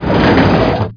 TRAINDR2.WAV